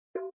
thump.mp3